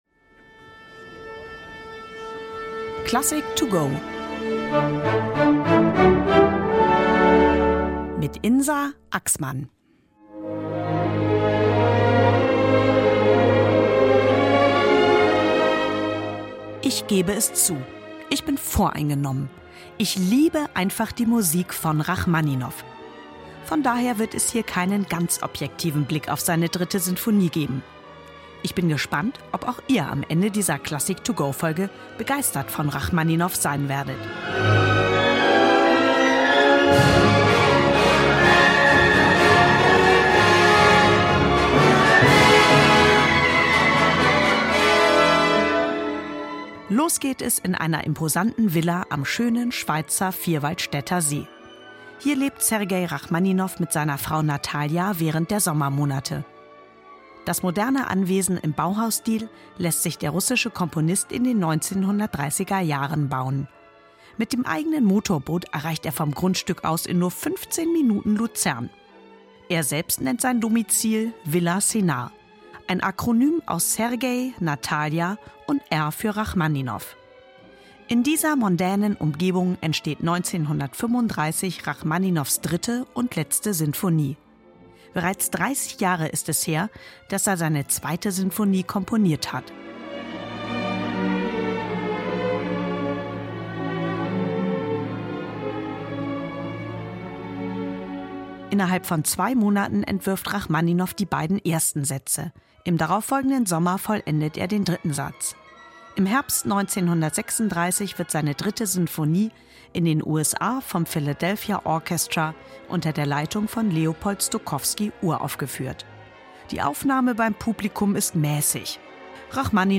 der digitalen Werkeinführung.